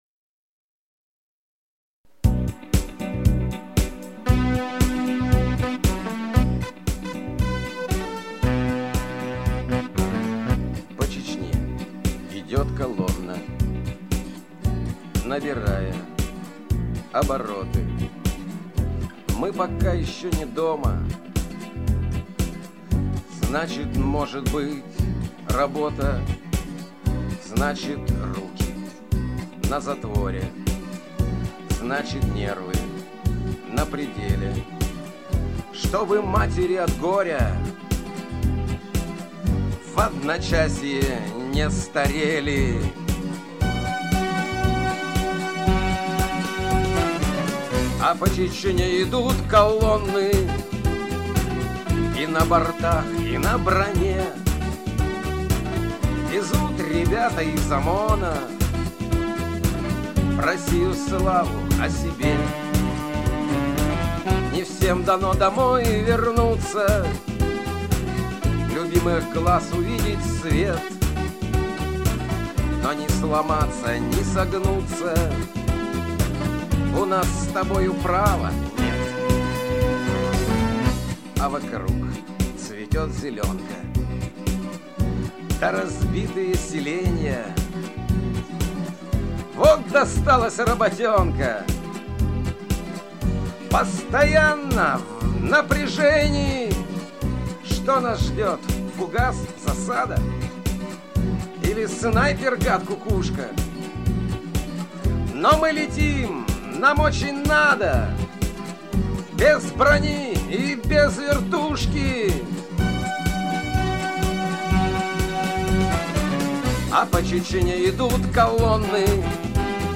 Музыкальный хостинг: /Авторская песня